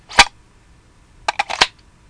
枪械 " Smg重新装弹
描述：步枪重装的声音。使用Nerf剪辑模拟声音。我做的。
Tag: 步枪 武器